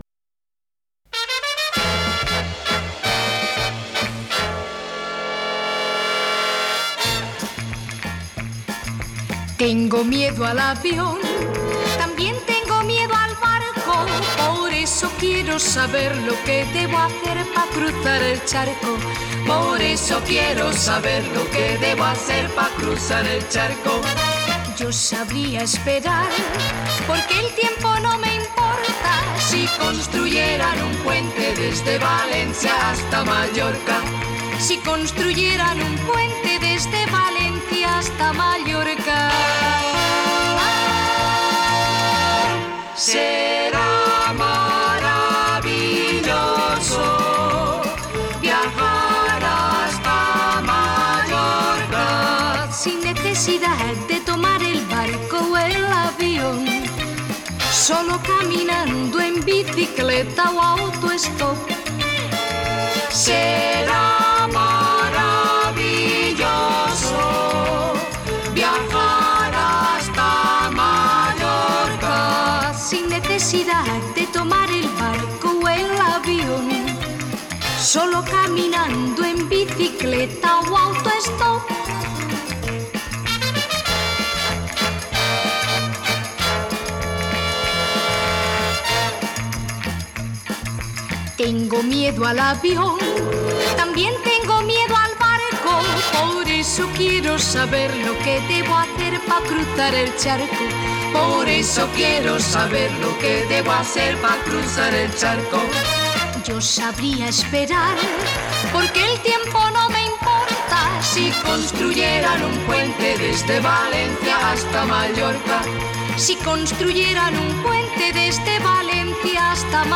música pop